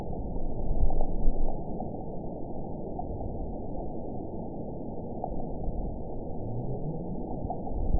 event 922105 date 12/26/24 time 11:50:44 GMT (5 months, 3 weeks ago) score 9.36 location TSS-AB03 detected by nrw target species NRW annotations +NRW Spectrogram: Frequency (kHz) vs. Time (s) audio not available .wav